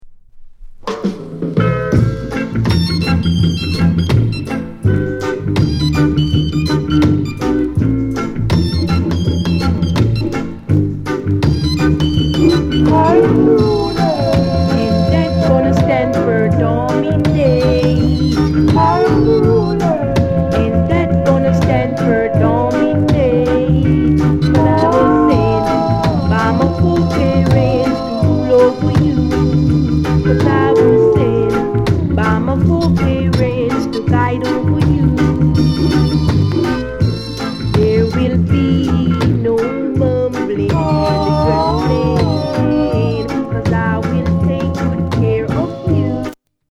WICKED ROCKSTEADY